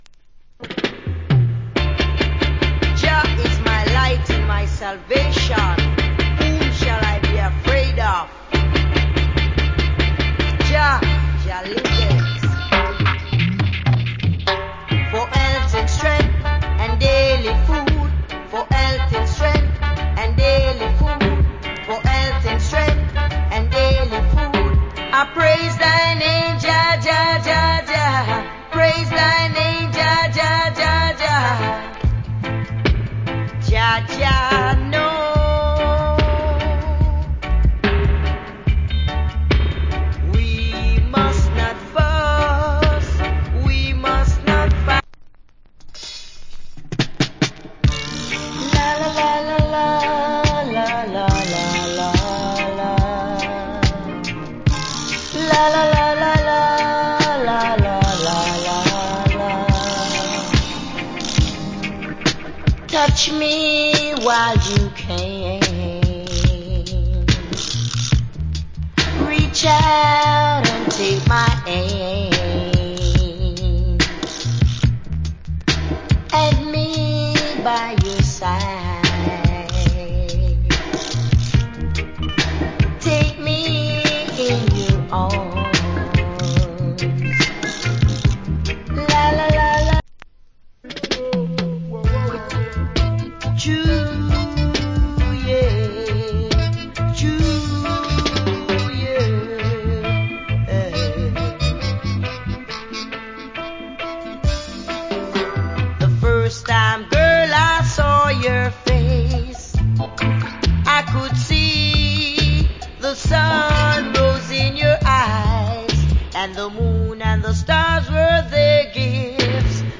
REGGAE ROOTS ROCK
Early 80's Nice Roots Rock & Reggae Compilation Album.